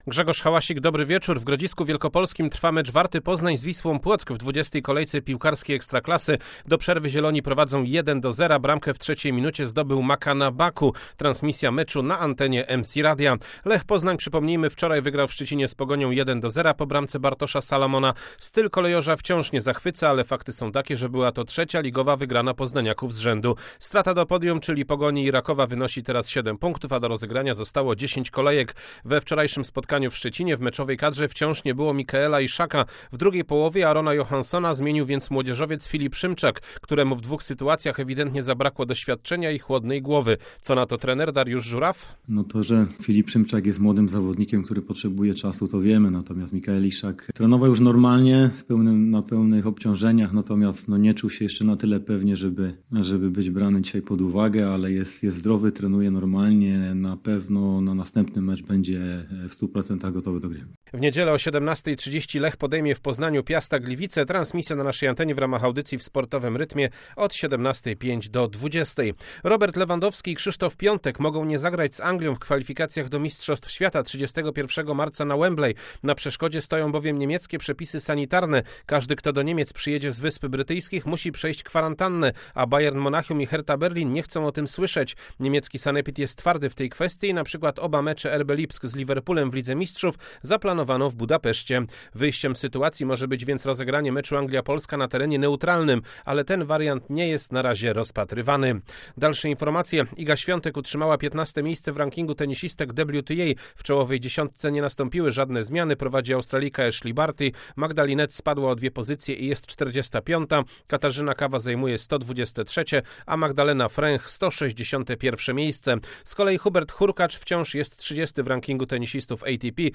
08.03.2021 SERWIS SPORTOWY GODZ. 19:05